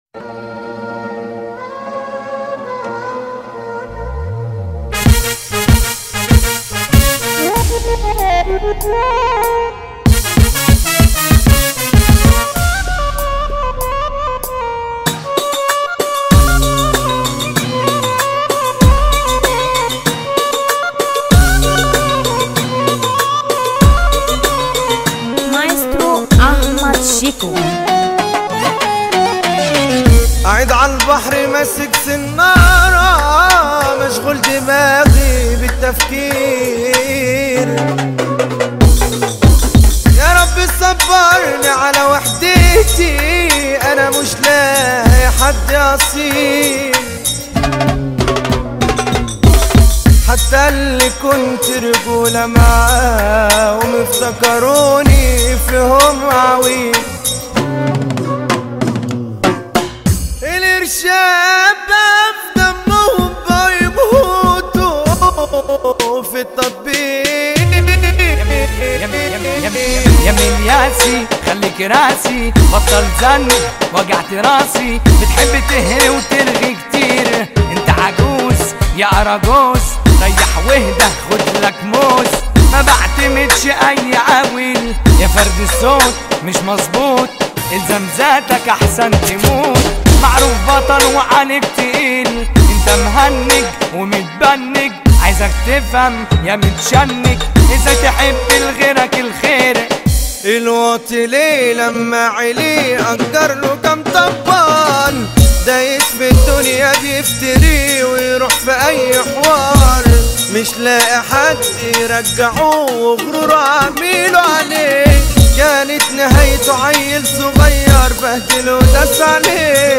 اغاني شعبي ومهرجانات